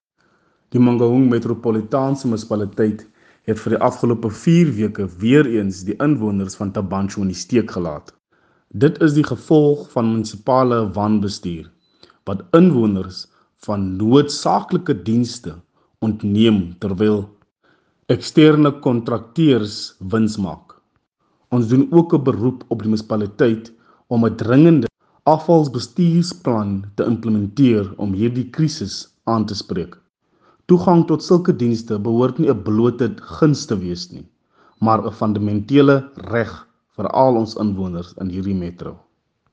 Afrikaans by Cllr Lyle Bouwer.
Afr-voice-Lyle.mp3